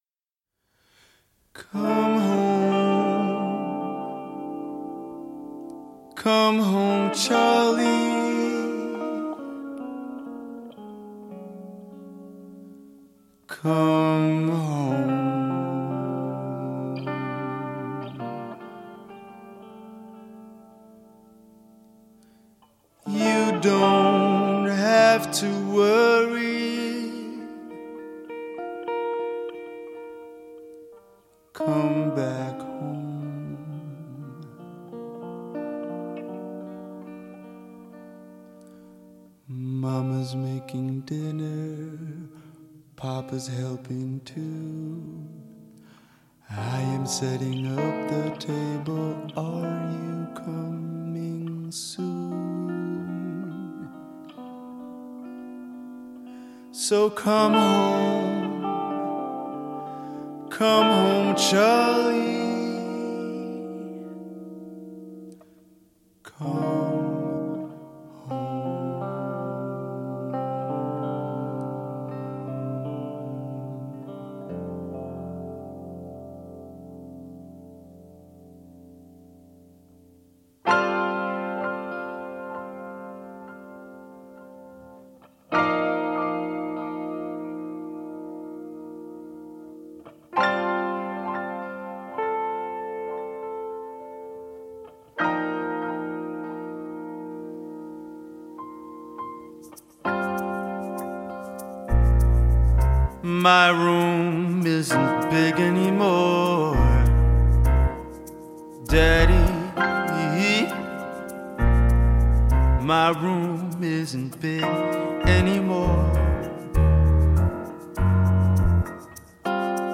guitar
piano
drums